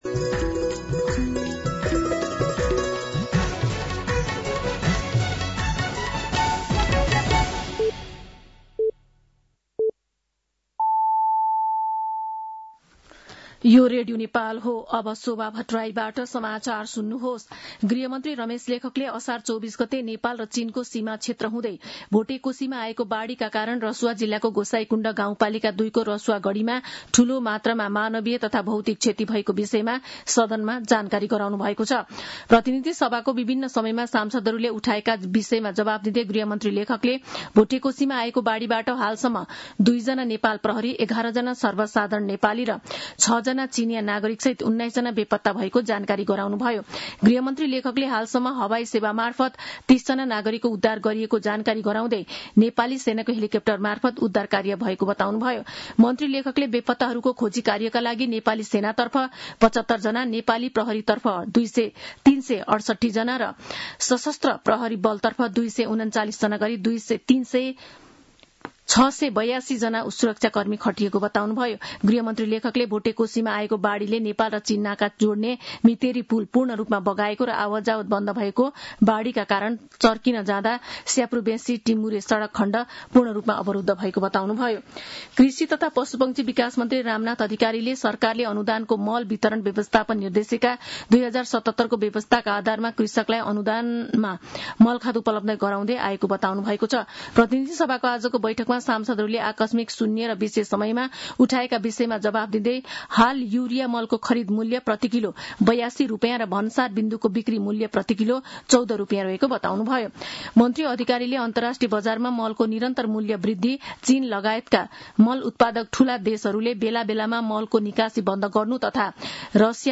साँझ ५ बजेको नेपाली समाचार : २५ असार , २०८२